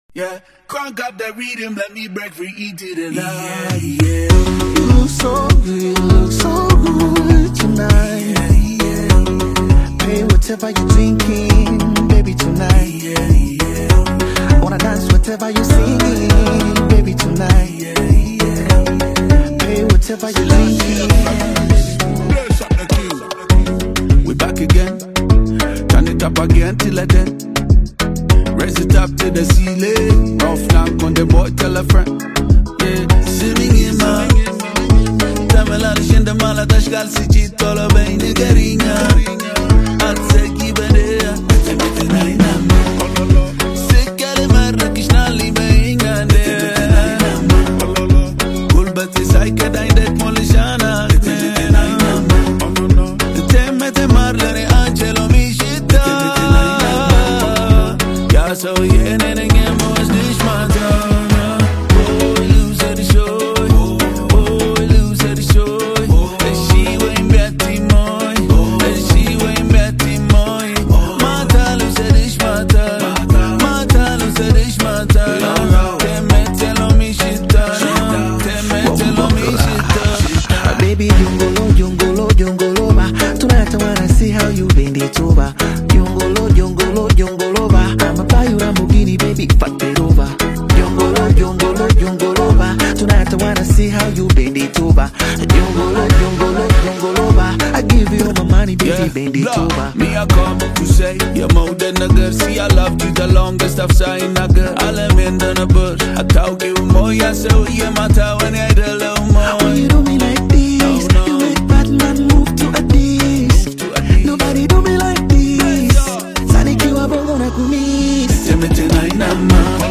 Bongo Flava
rich instrumentation, catchy rhythm, and emotional lyrics